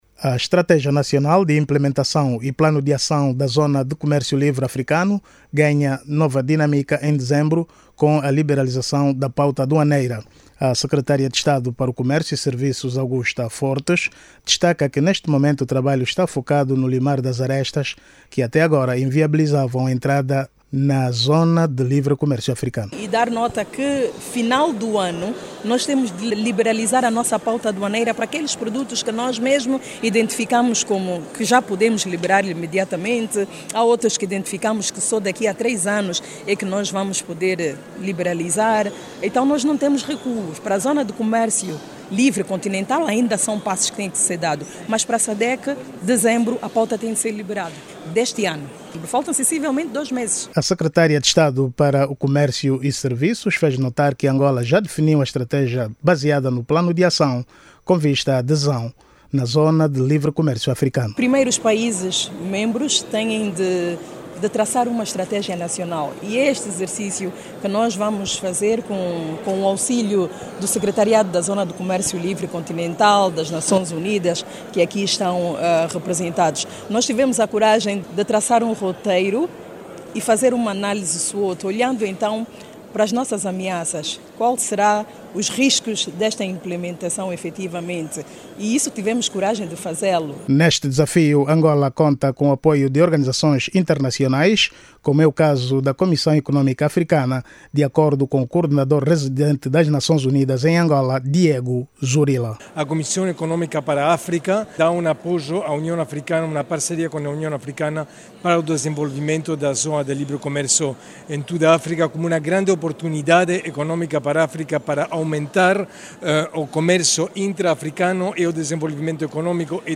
A informação foi avançada hoje, em Luanda, pela Secretária de Estado para o Comércio e Serviços, Augusta Fortes, durante o workshop sobre a Estratégia Nacional e o Plano de Acção da Zona de Comércio Livre Continental Africana.